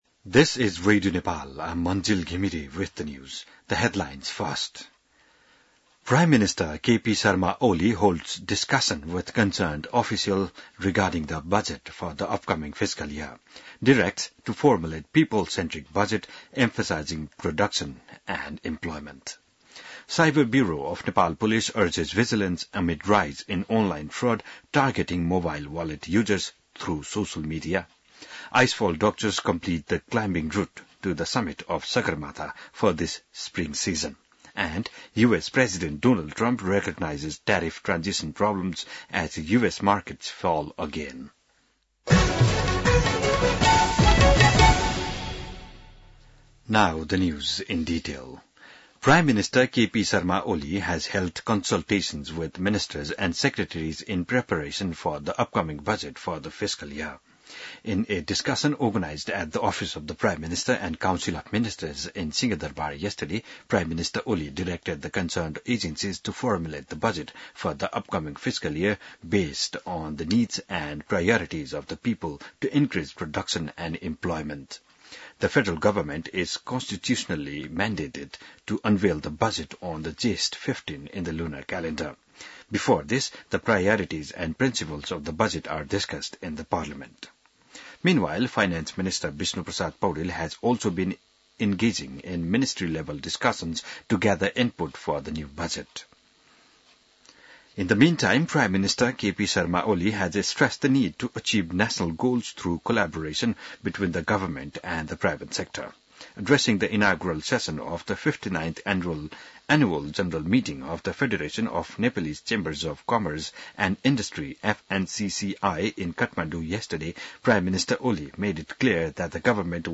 बिहान ८ बजेको अङ्ग्रेजी समाचार : २९ चैत , २०८१